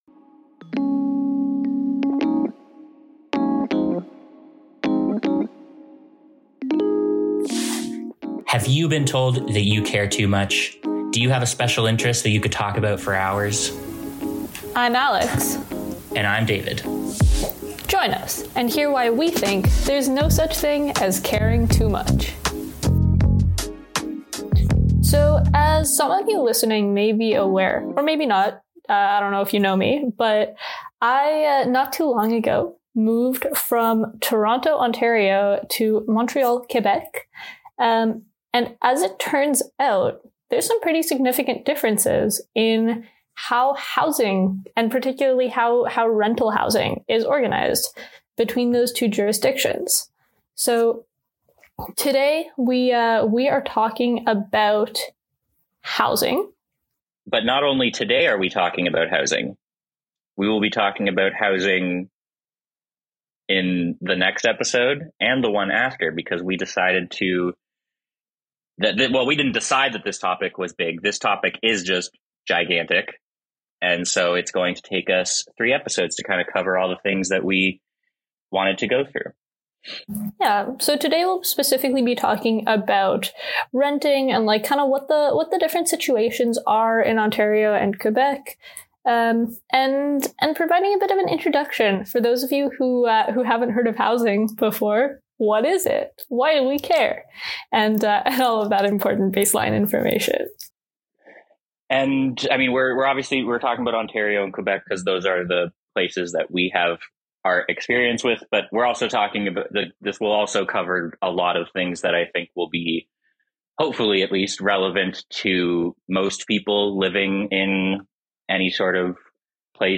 Two young professionals